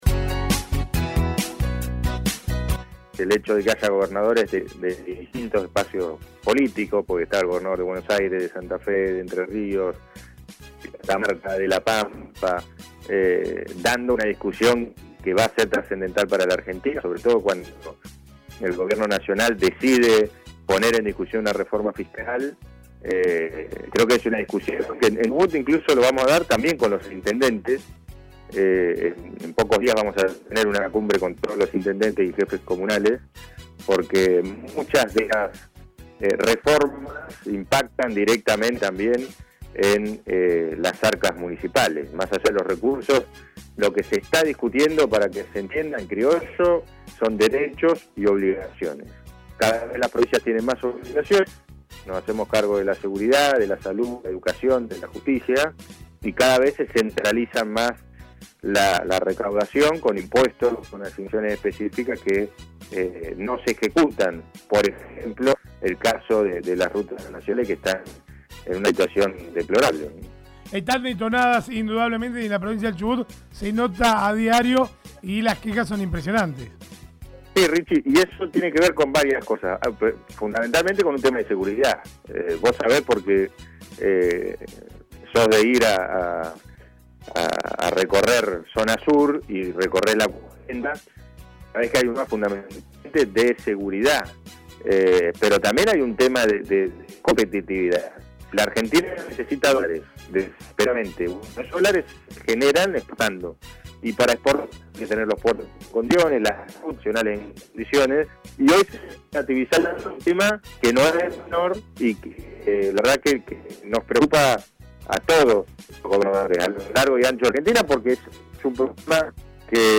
El gobernador de Chubut, Ignacio Torres, habló en exclusiva con LA MAÑANA DE HOY a minutos de cerrar su alocución en el Consejo Federal de Inversión sobre la Hoja de ruta para el desarrollo, evento llevado a cabo en Paraná, Entre Ríos.